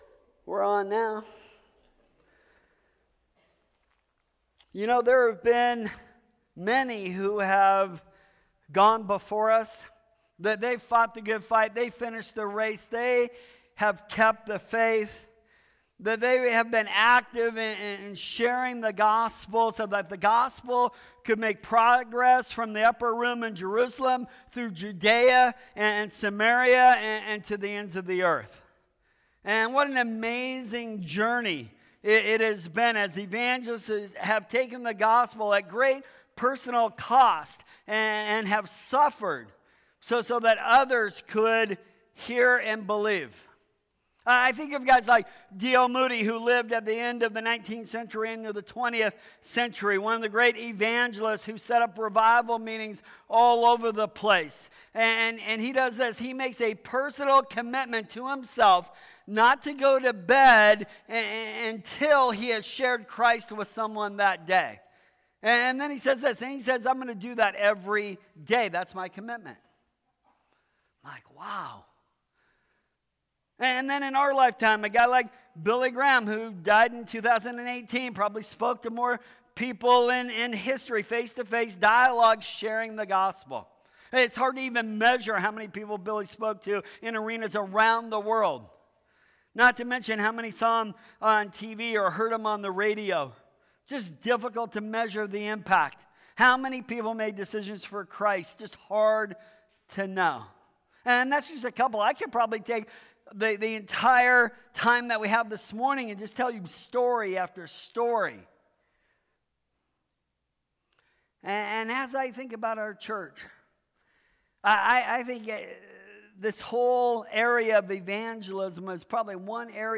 Sermons | New Creation Fellowship